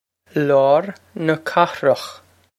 Pronunciation for how to say
Lowr na kha-rokh
This is an approximate phonetic pronunciation of the phrase.